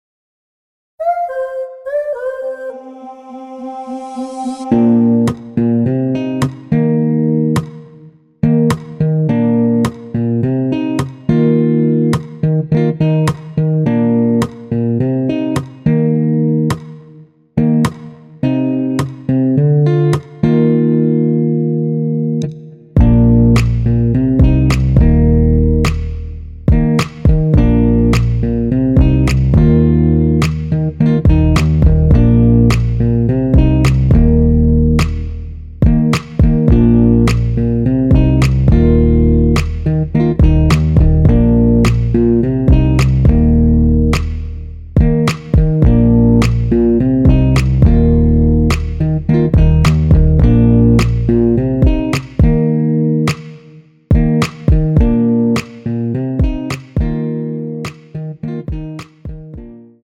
원키에서(-1)내린 MR입니다.
Bb
앞부분30초, 뒷부분30초씩 편집해서 올려 드리고 있습니다.
중간에 음이 끈어지고 다시 나오는 이유는